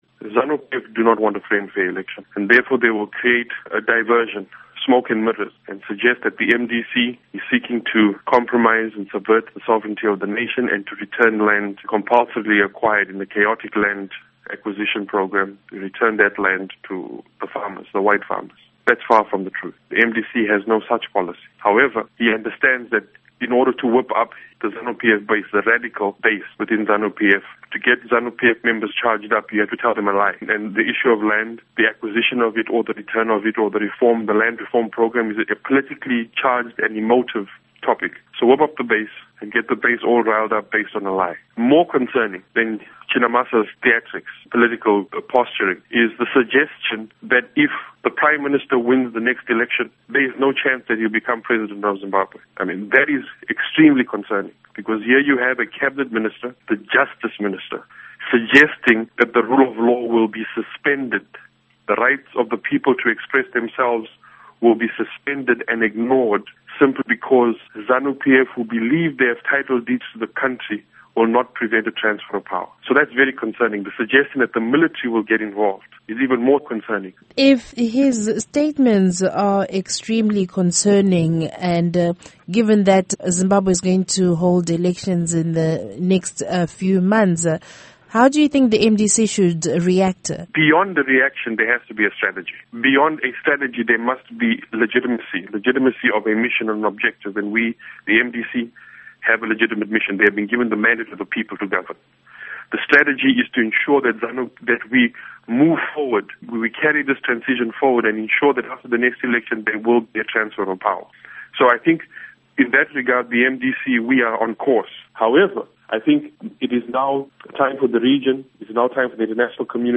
Interview Simba Makoni